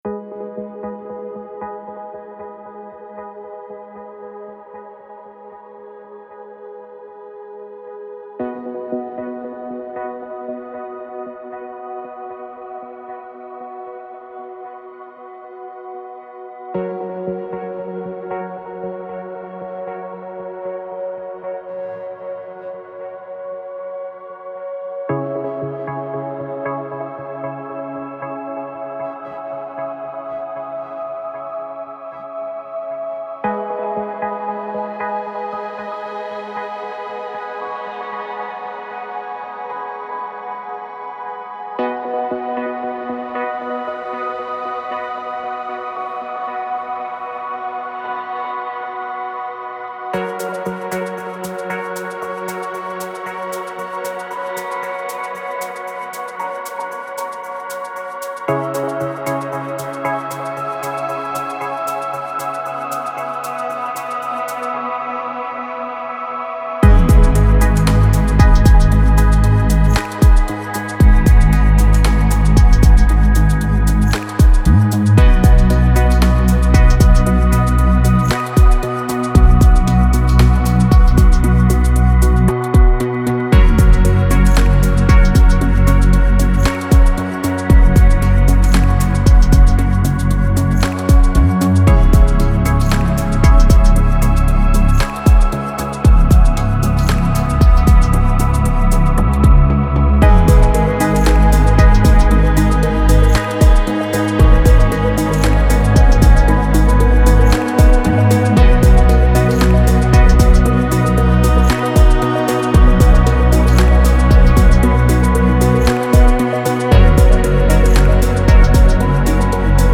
سبک الکترونیک